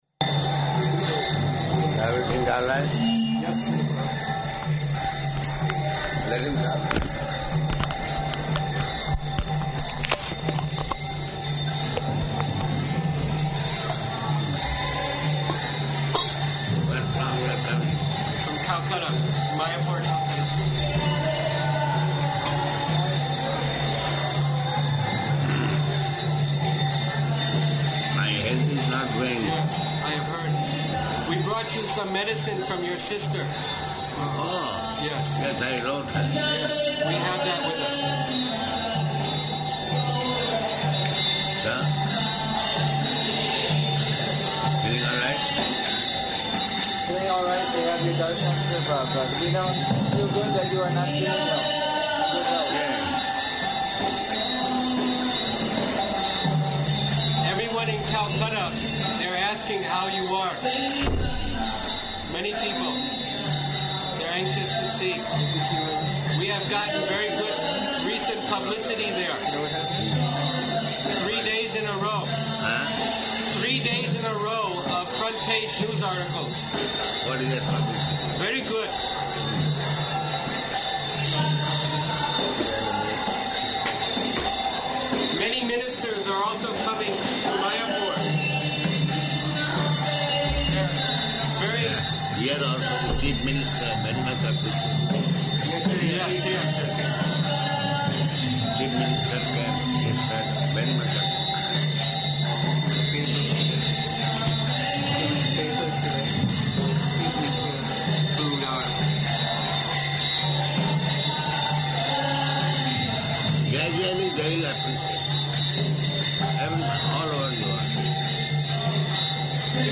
Room Conversation
Location: Hyderabad